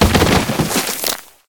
liondead.ogg